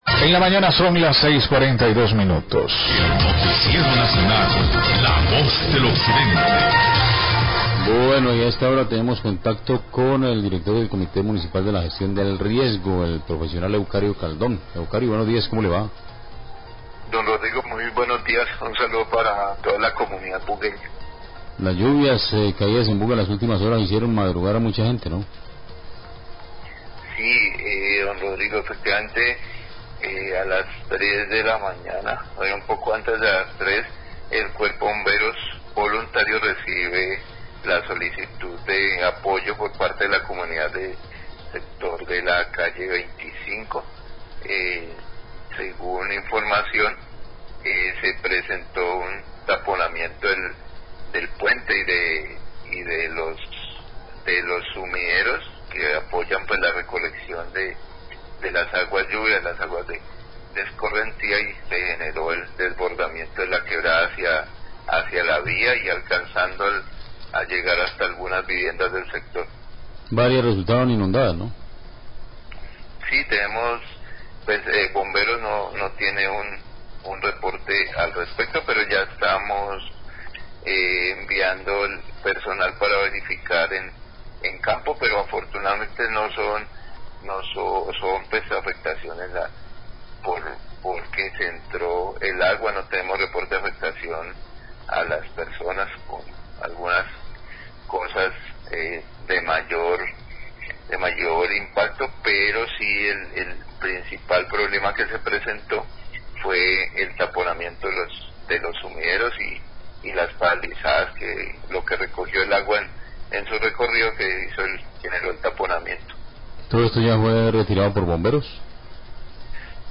Radio
Eucario Caldón, Secretario de Gestión Riesgo de Buga, entrega un balance de la emergencias atendidas por las inundaciones, crecientes súbitas y deslizamientos generados por las fuertes lluvias de anoche, en zona urbana y rural del municipio.